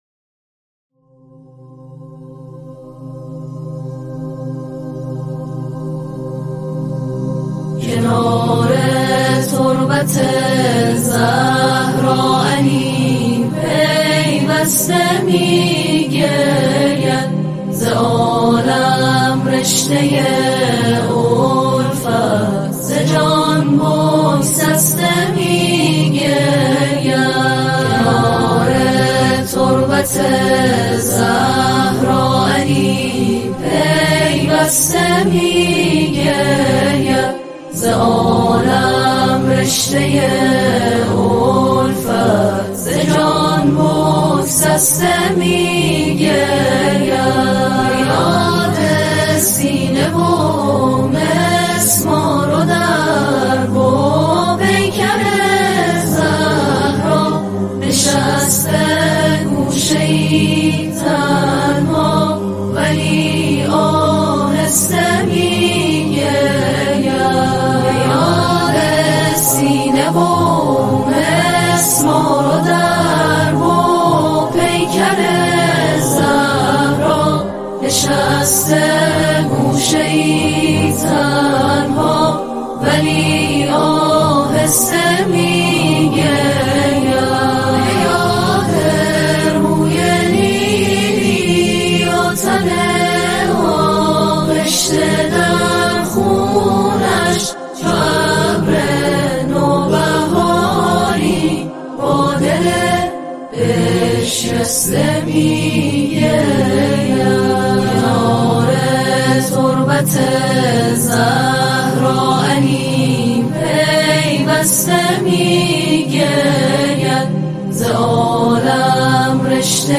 نماهنگ سرود